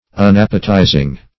unappetizing - definition of unappetizing - synonyms, pronunciation, spelling from Free Dictionary